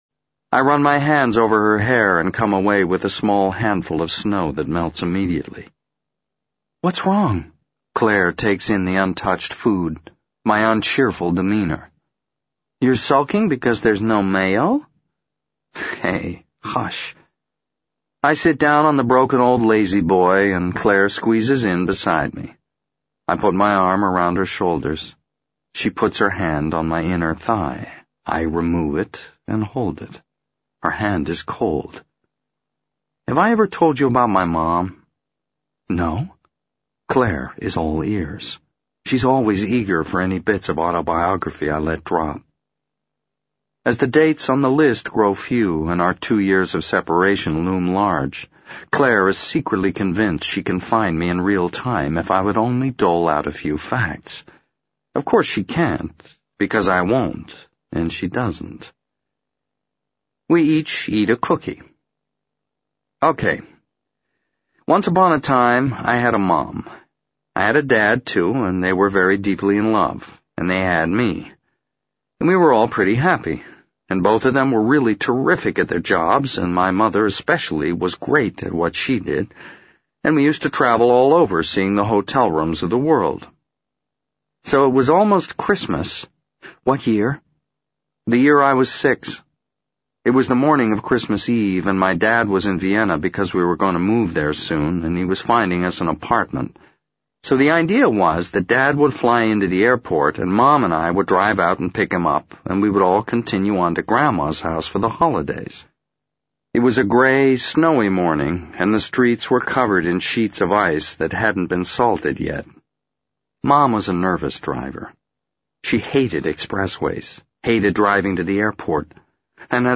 在线英语听力室【时间旅行者的妻子】92的听力文件下载,时间旅行者的妻子—双语有声读物—英语听力—听力教程—在线英语听力室